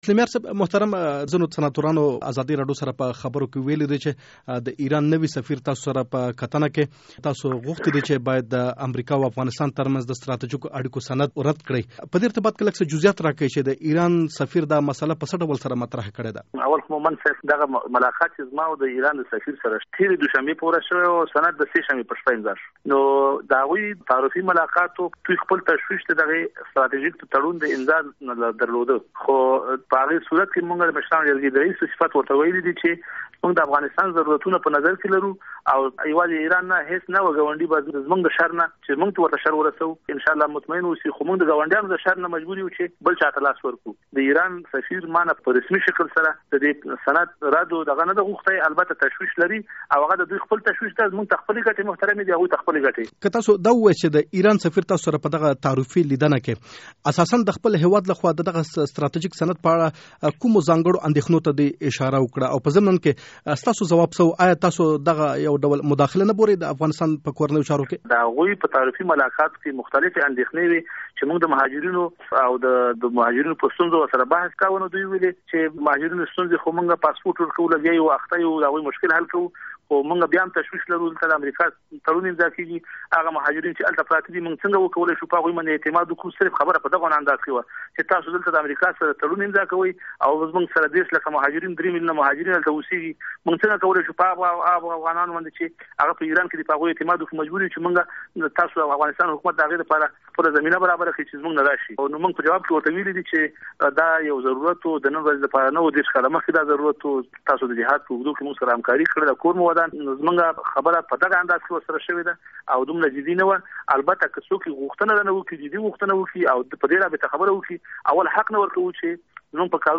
له فضل هادي مسلمیار سره مرکه